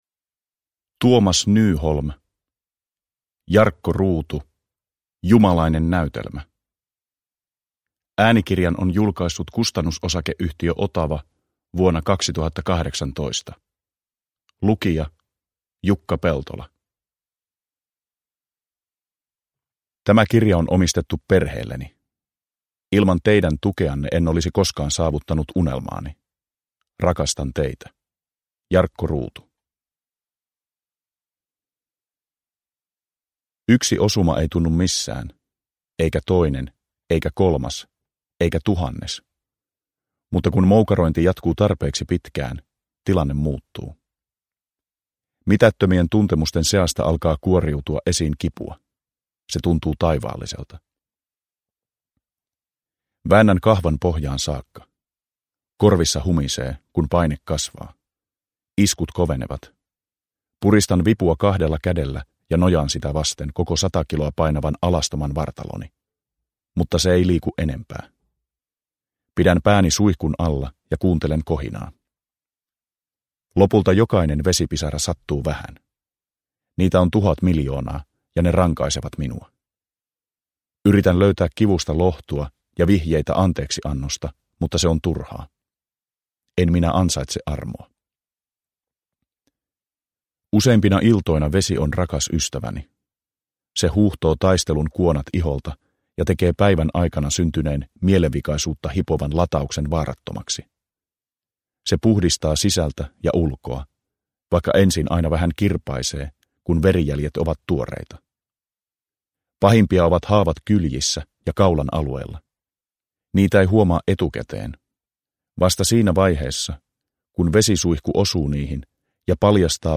Jarkko Ruutu – Ljudbok – Laddas ner
Uppläsare: Jukka Peltola